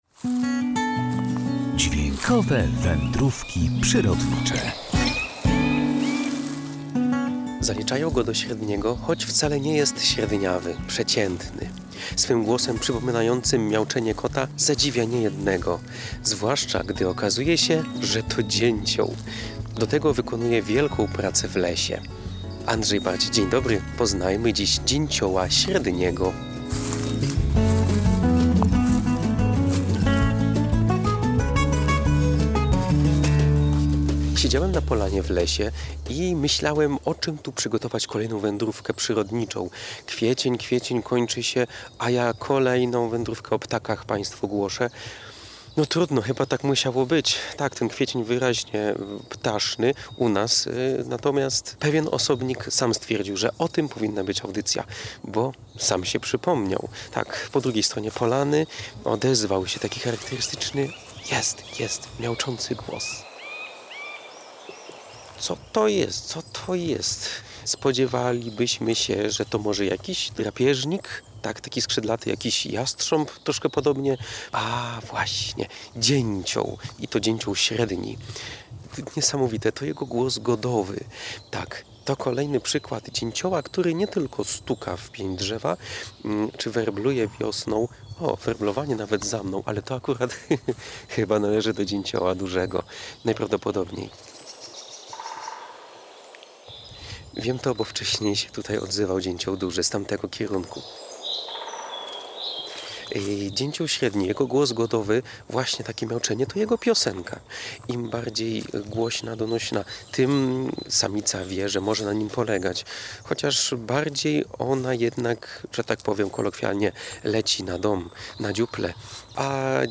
Dźwiękowe Wędrówki Przyrodnicze • Zaliczają go do średnich, choć wcale nie jest średniawy. Swym głosem przypominającym miauczenie kota zadziwia niejednego, zwłaszcza gdy okazuje się, że to dzięcioł.